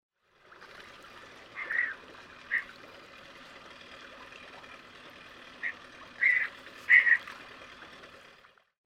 В коллекции два натуральных аудиофрагмента с голосами этих удивительных птиц.
Звук бурого американского пеликана